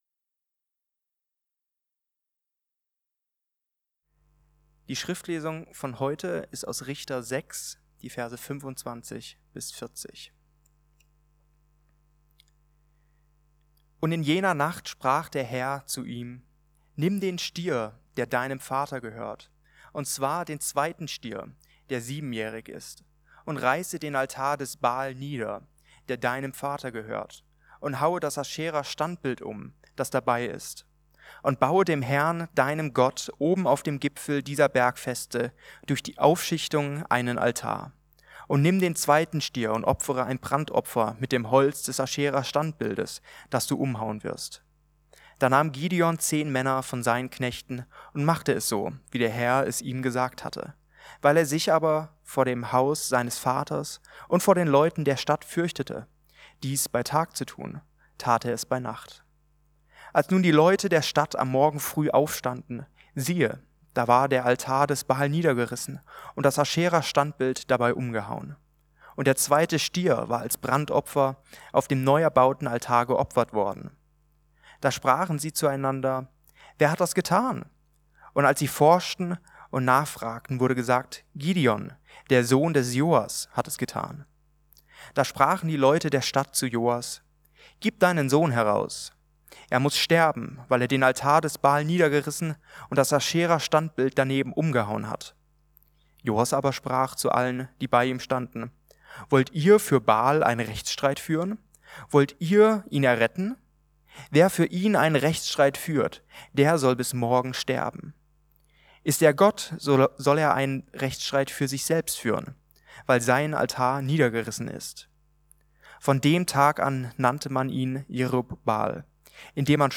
Zweite Schritte ~ Mittwochsgottesdienst Podcast